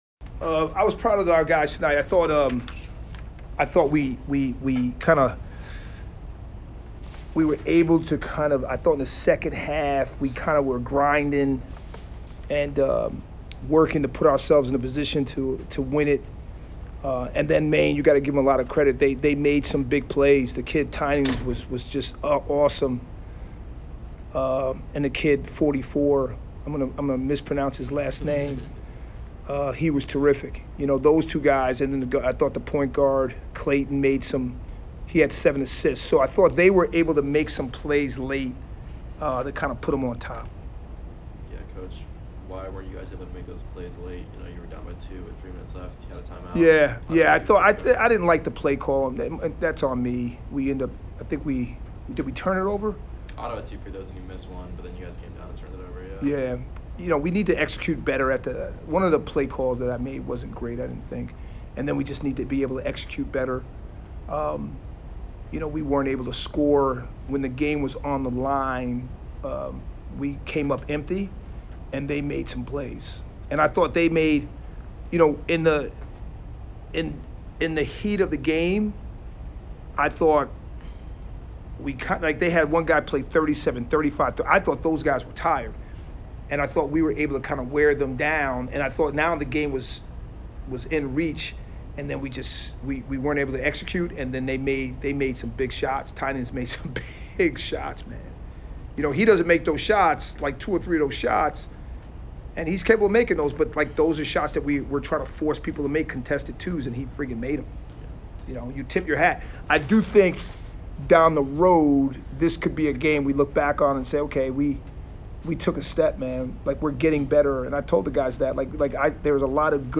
Maine Postgame Interview (12-6-23)